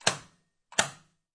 临时区解锁.mp3